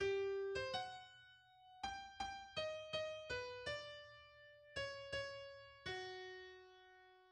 Genre Sonate
Effectif piano
La sonate n'est pas dans la forme classique typique en trois ou quatre  mouvements contrastés, mais se compose d'un unique mouvement centré autour de la tonalité de si mineur.
Bien que la pièce porte la tonalité de si mineur, Berg fait un usage fréquent du chromatisme, de la gamme par tons et errant entre les principales tonalités, ce qui donne une sensation d'instabilité tonale seulement résolue dans les dernières mesures[2].